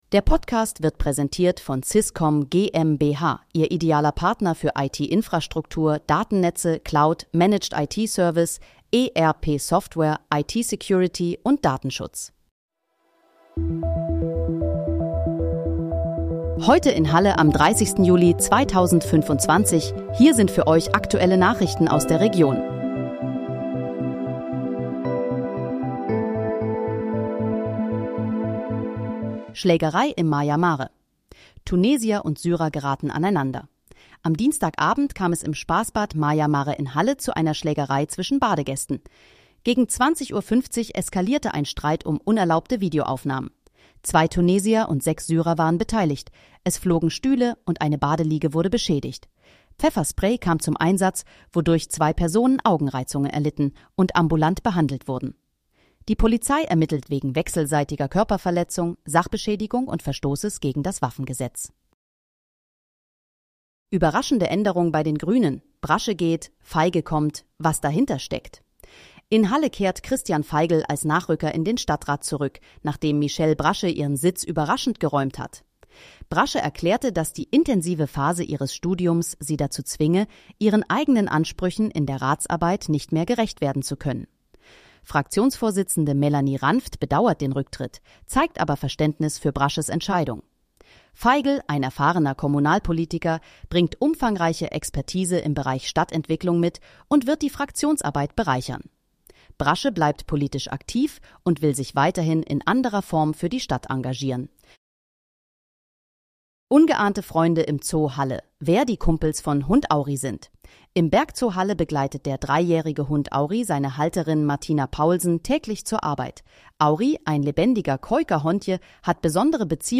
Heute in, Halle: Aktuelle Nachrichten vom 30.07.2025, erstellt mit KI-Unterstützung
Nachrichten